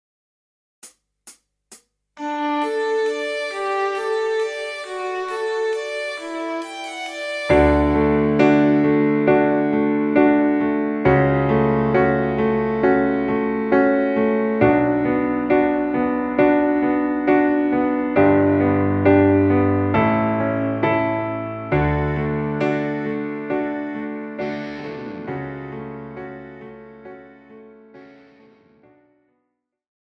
Žánr: Rock
BPM: 135
Key: D
MP3 ukázka